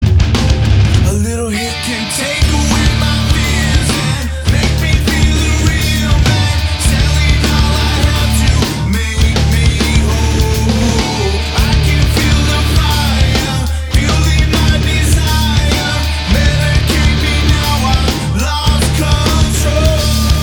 • Качество: 320, Stereo
мужской вокал
громкие
мелодичные
nu metal